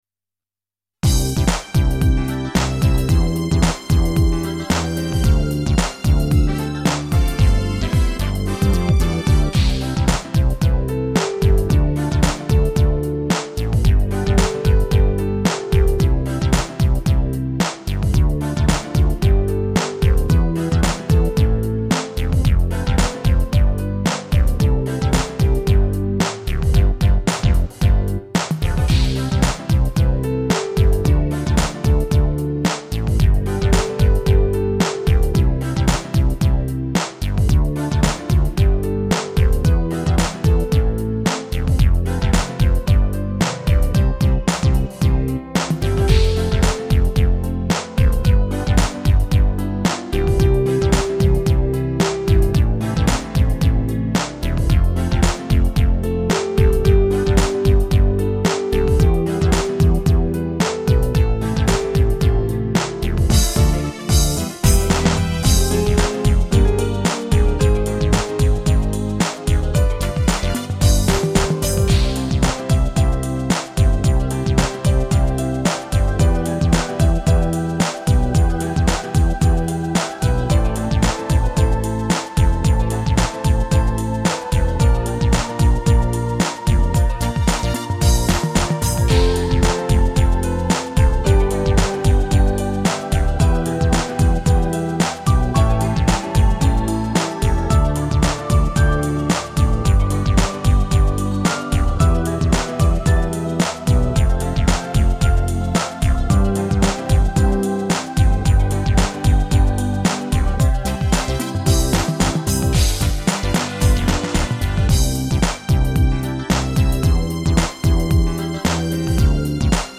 サビの終わりの所で音域が広すぎるそうです。
メロディーと伴奏だけでは、やっぱり足りない
作曲法の規則にとらわれずに作ったピアノソロ曲に、SOL2の自動伴奏機能で